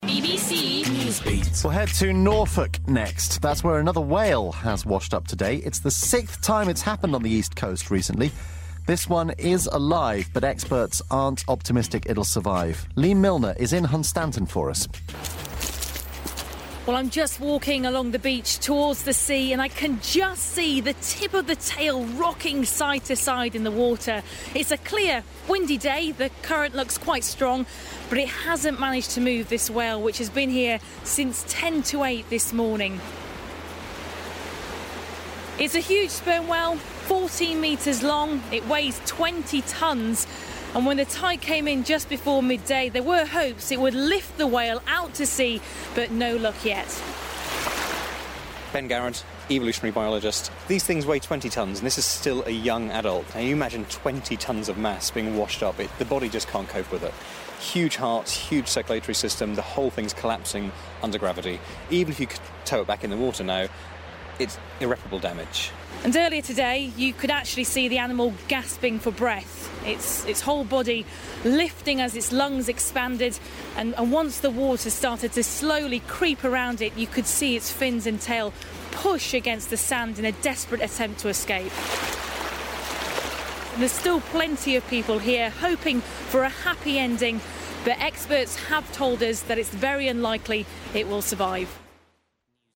Report on the beached whale in Norfolk